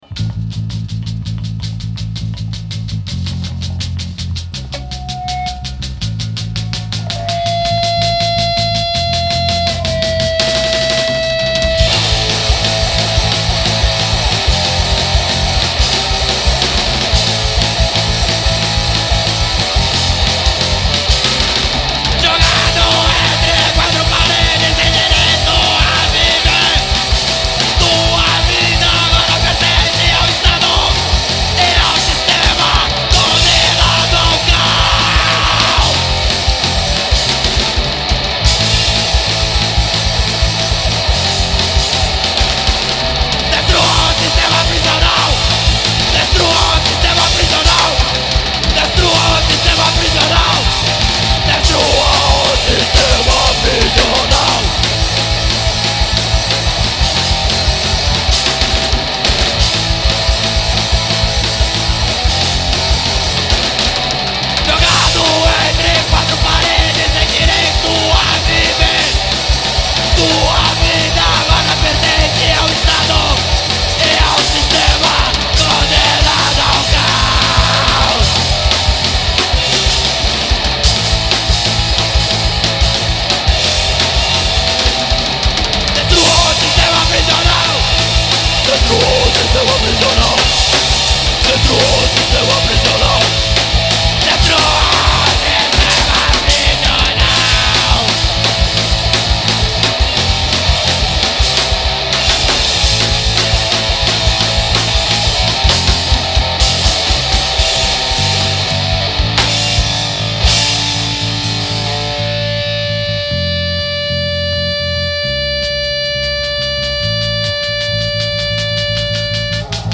EstiloPunk Rock